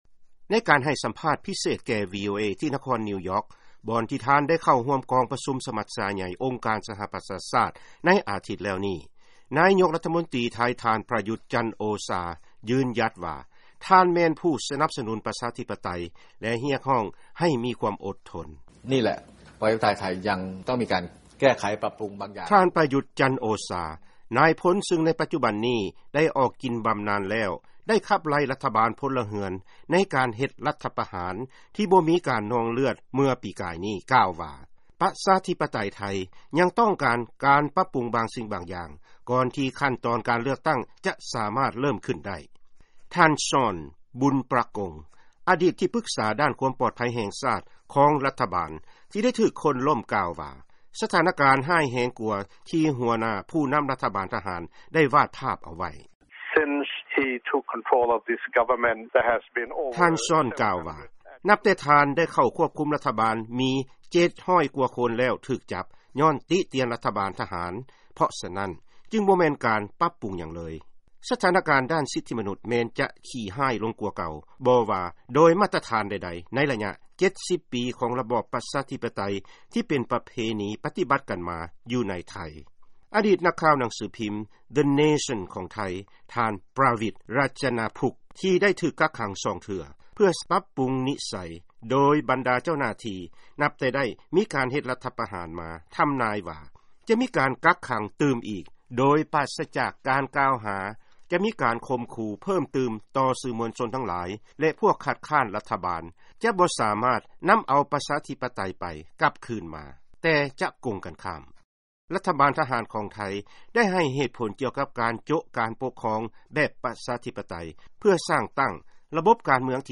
ຟັງການສຳພາດ ນາຍົກລັດຖະມົນຕິໄທ ທ່ານປຣະຍຸດ ສັນໂອຊາ